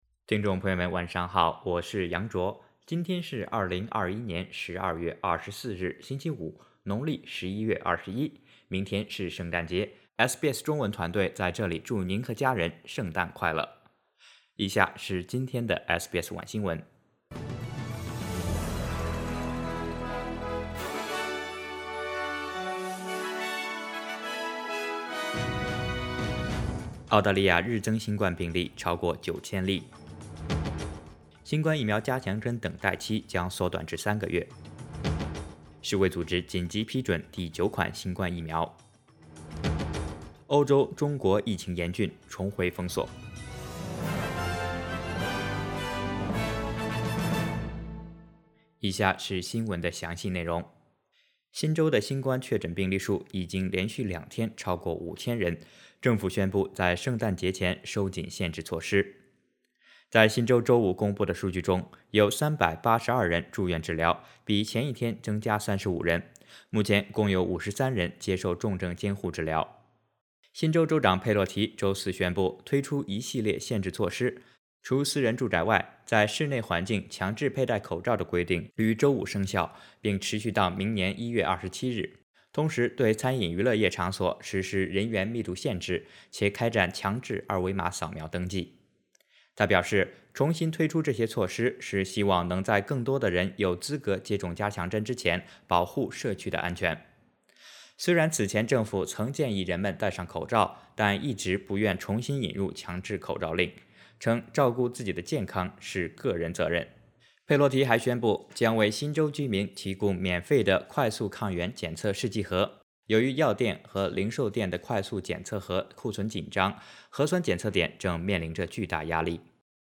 SBS晚新闻（2021年12月24日）